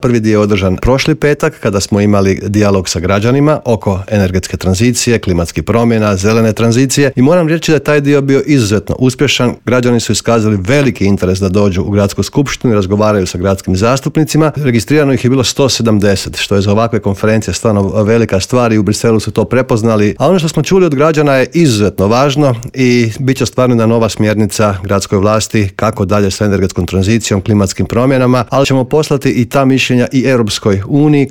ZAGREB - Povodom međunarodne Konferencije "Zelena tranzicija pokreće europske gradove" predsjednik Gradske skupštine Grada Zagreba Joško Klisović gostovao je Intervjuu Media servisa.